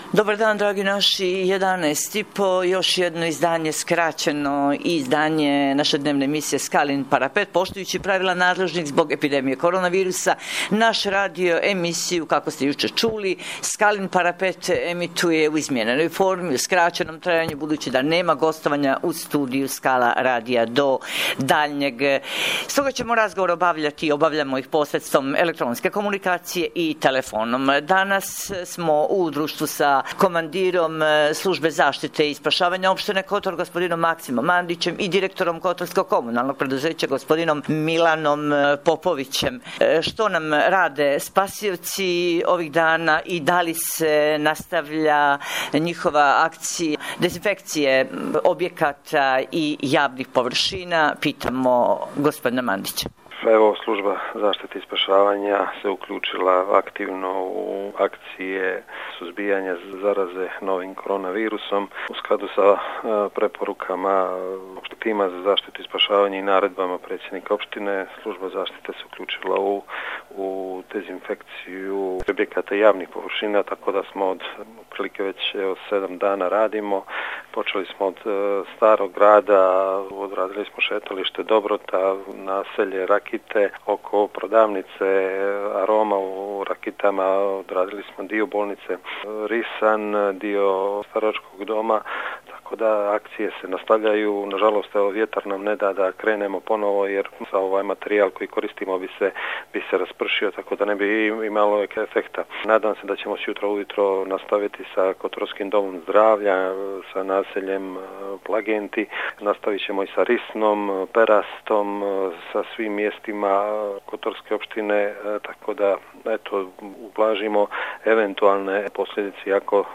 Stoga ćemo razgovore obavljati posredstvom elektronske komunikacije i telefonom, kako bi javnost bila pravovremeno imnformisana o svemu što cijenimo aktuelnim, preventivnim i edukativnim u danima kada moramo biti doma.